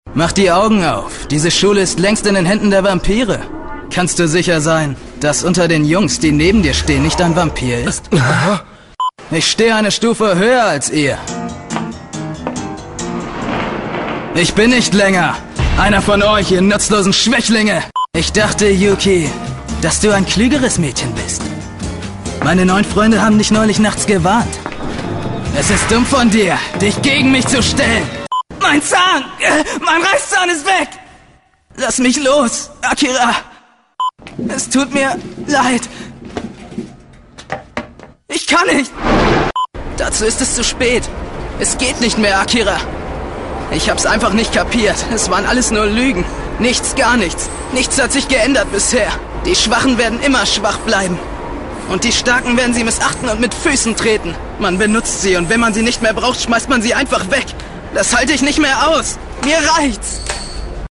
Jugendliche, dynamische, variable, markante, unverbrauchte, ausdrucksstarke, kräftige und facettenreiche Stimme.
Sprechprobe: Werbung (Muttersprache):
Young, energetic, variable, distinctive, expressive, powerful and multifarious voice.